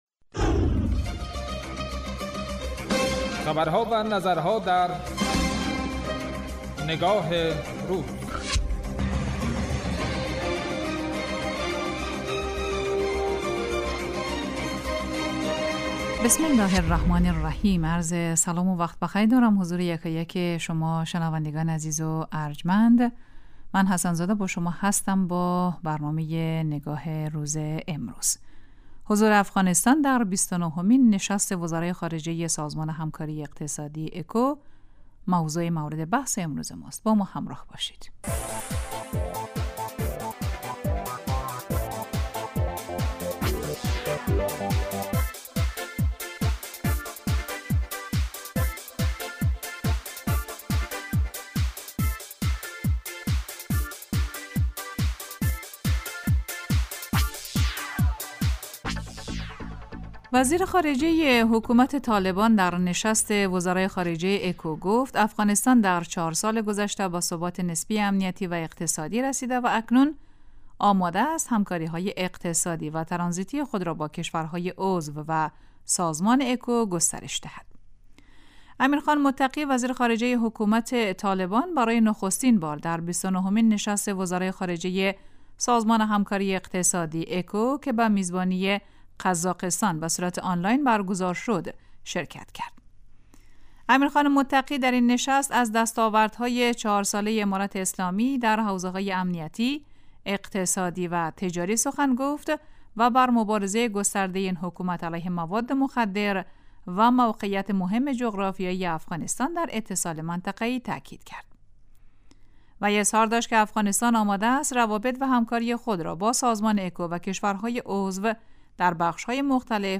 برنامه تحلیلی نگاه روز